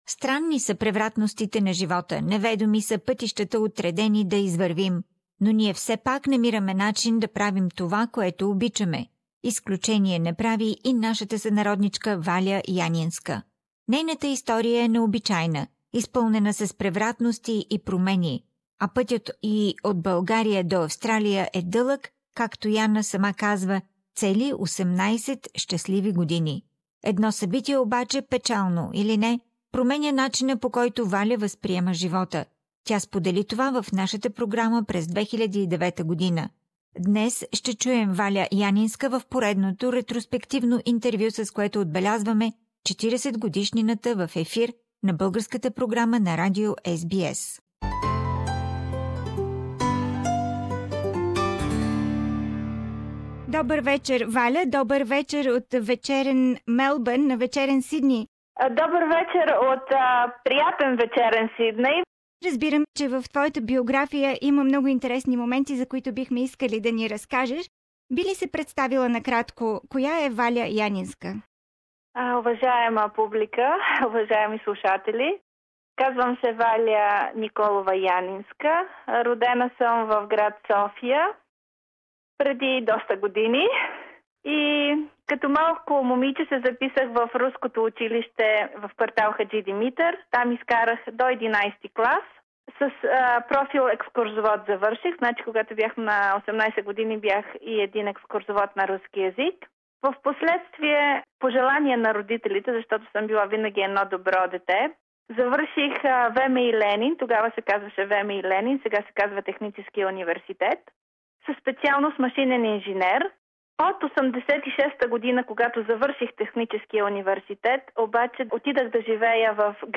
ретроспективно интервю